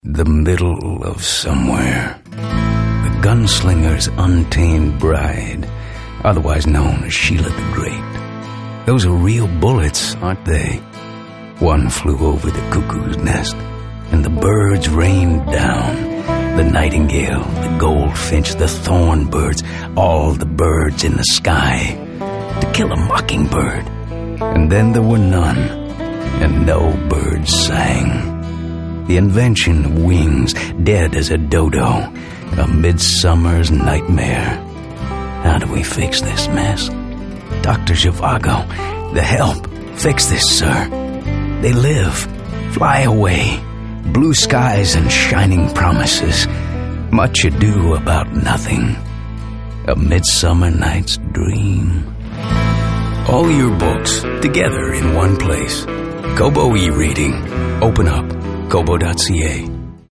GoldRadio - Campaign
SILVER - Radio Single: "The Birds"